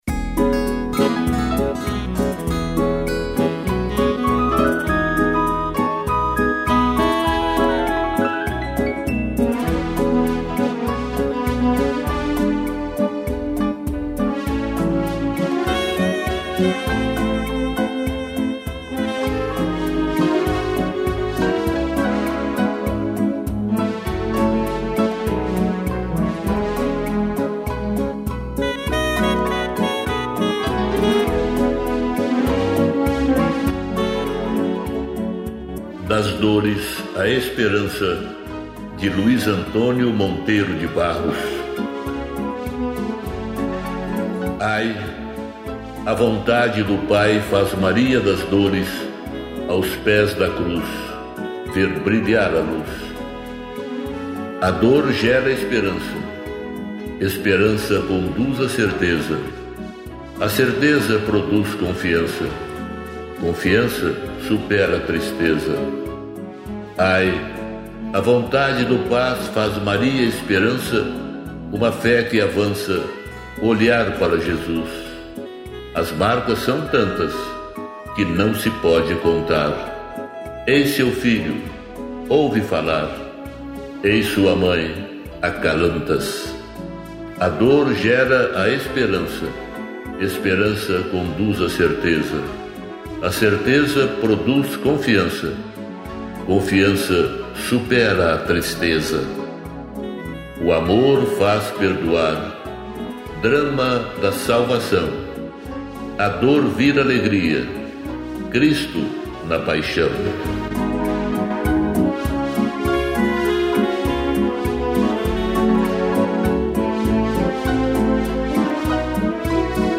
piano, sax e violão